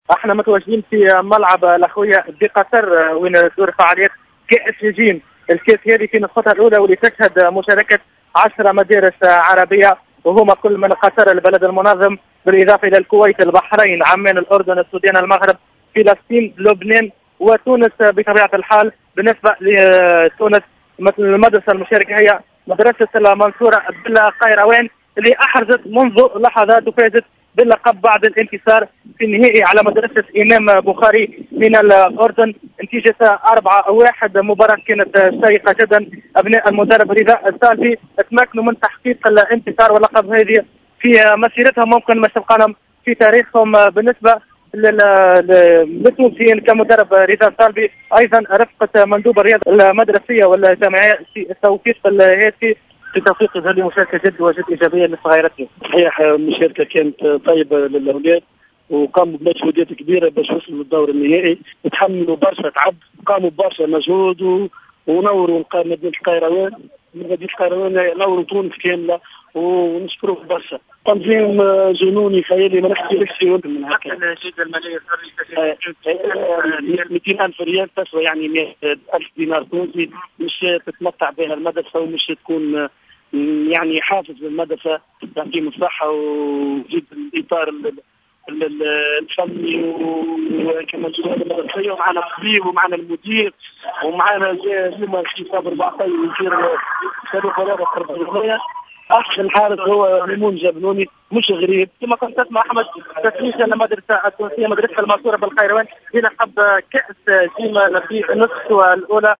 مواكبة للحظات تتويج الفريق مباشرة من العاصمة القطرية الدوحة